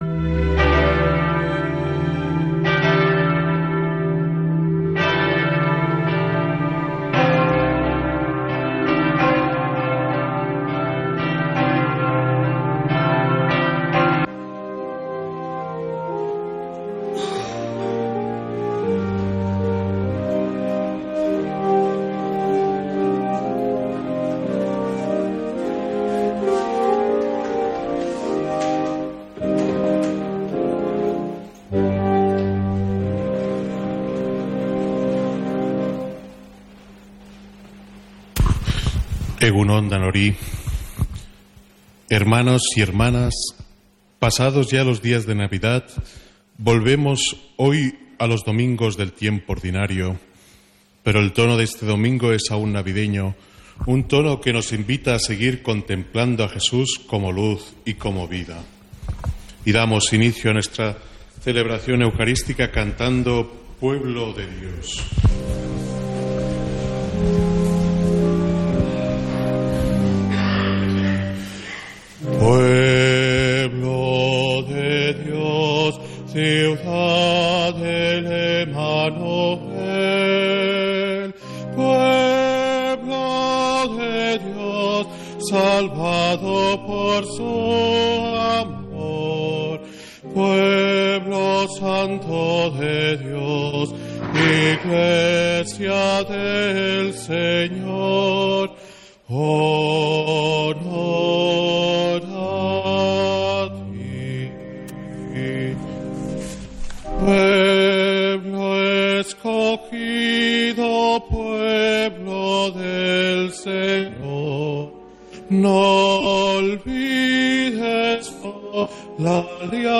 Santa Misa desde San Felicísimo en Deusto, domingo 19 de enero